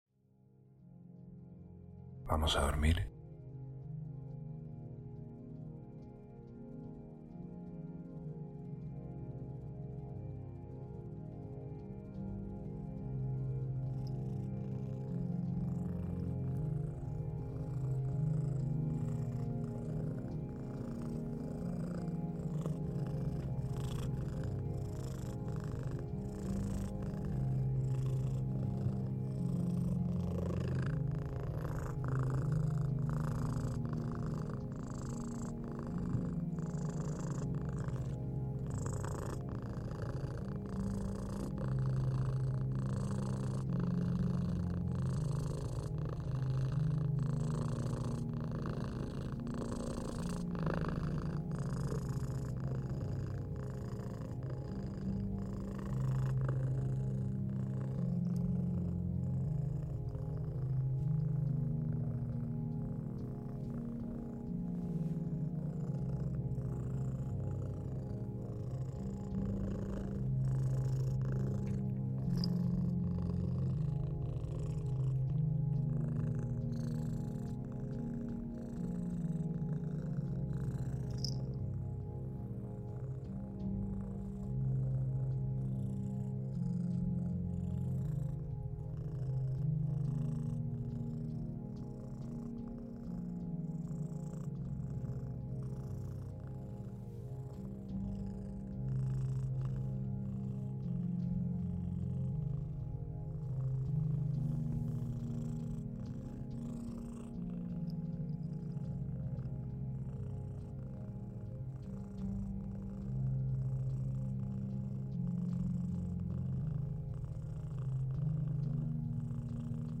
Ambientes para Dormir ✨ Refugio de gatitos
Experiencias inmersivas para ayudarte a dormir profundamente.